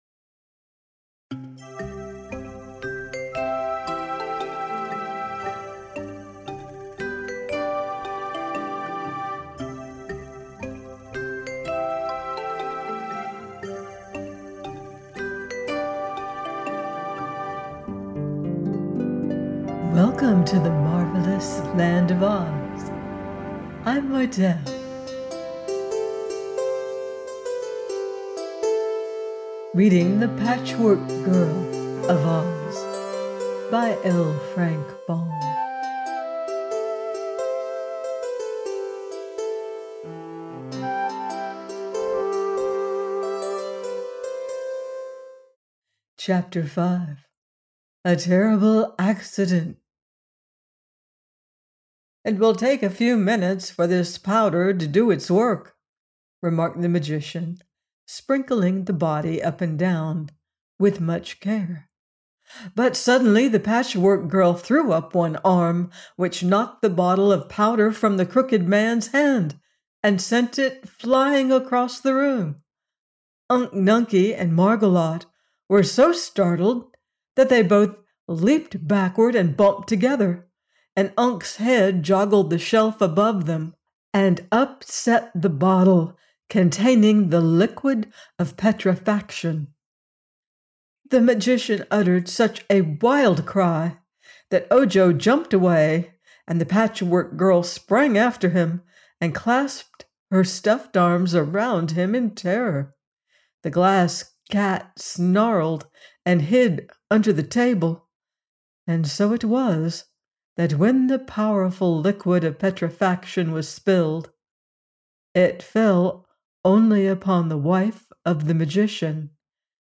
The Patchwork Girl of Oz – by L. Frank Baum - audiobook